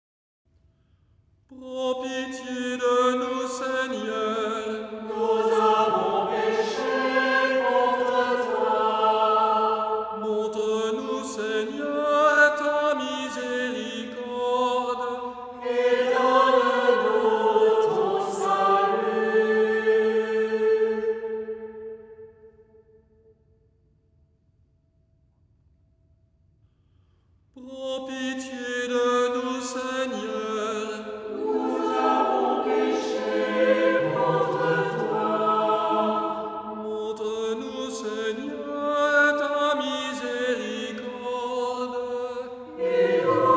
Si l’on dispose d’un petit groupe-choral (3 voix mixtes), ces pièces peuvent être chantées en polyphonie.
La seconde forme de l’Acte pénitentiel est un bref dialogue entre le prêtre et l’assemblée.